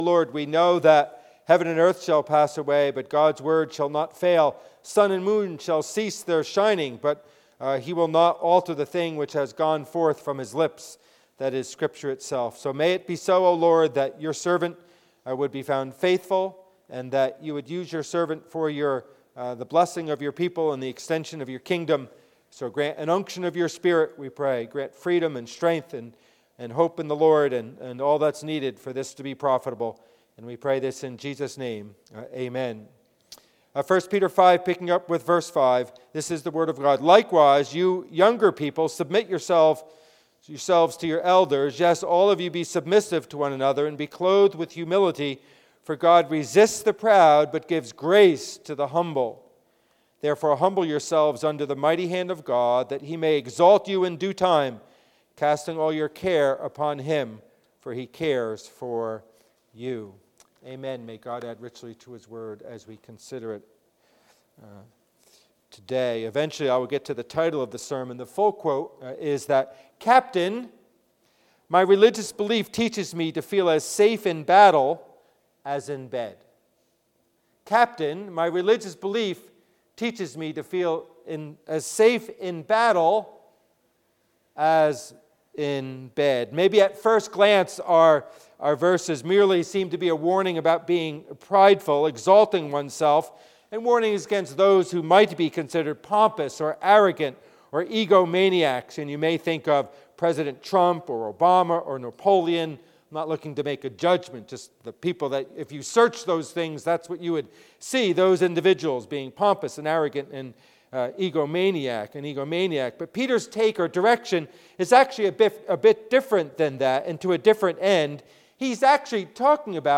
Passage: 1 Peter 5:5-7 Service Type: Worship Service